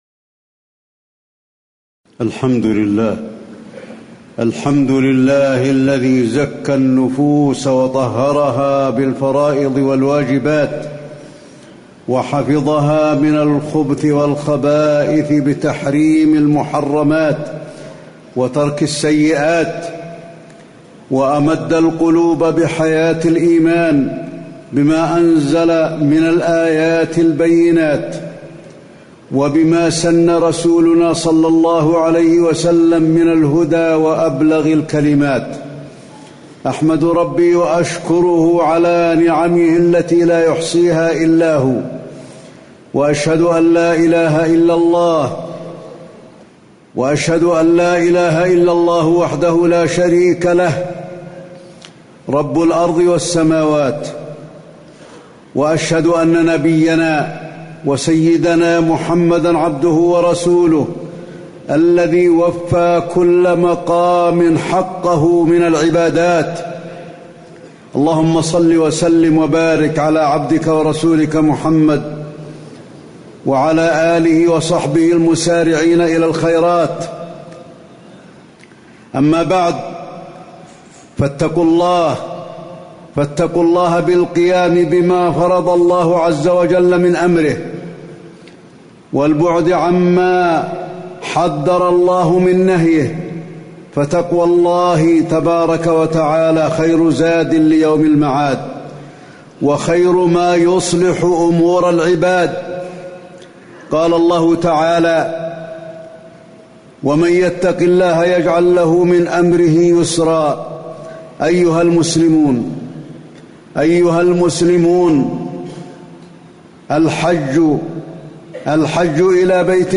تاريخ النشر ٢٦ ذو القعدة ١٤٣٨ هـ المكان: المسجد النبوي الشيخ: فضيلة الشيخ د. علي بن عبدالرحمن الحذيفي فضيلة الشيخ د. علي بن عبدالرحمن الحذيفي الحج فضائله وآدابه